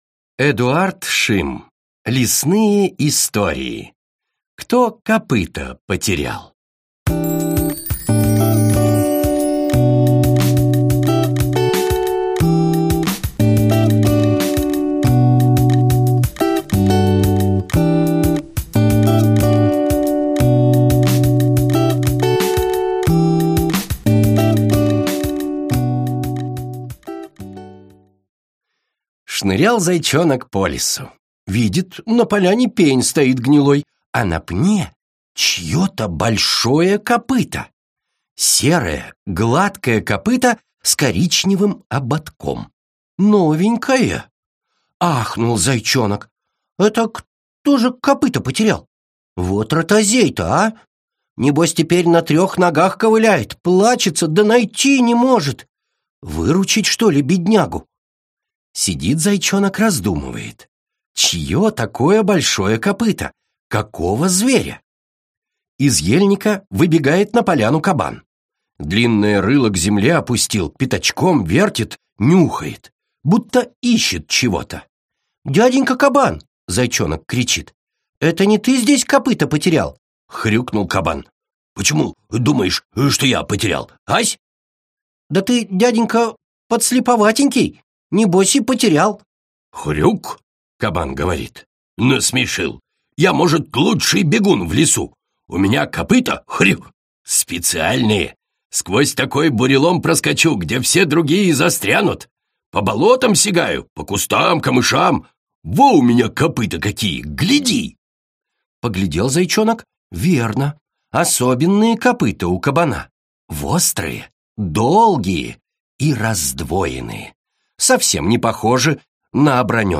Аудиокнига Лесные истории | Библиотека аудиокниг